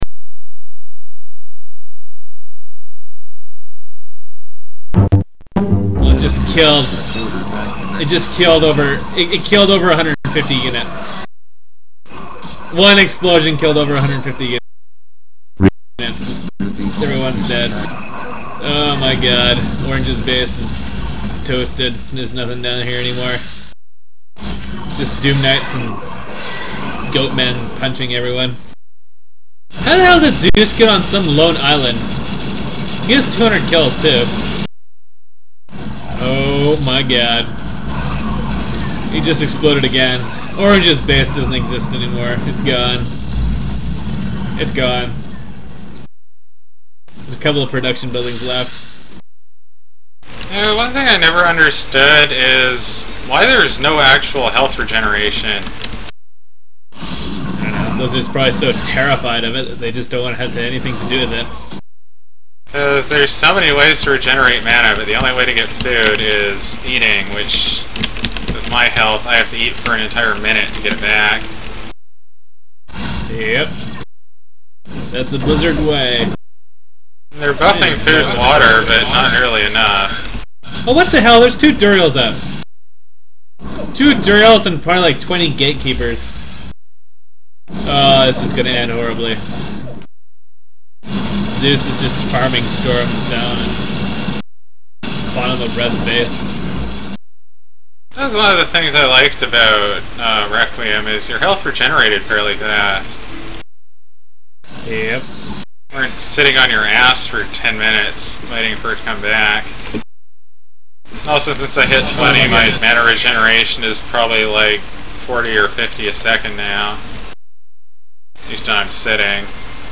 SS WeekWeek InfoReplay PackDownload MapsGood MapsMP3 Conversations